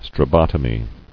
[stra·bot·o·my]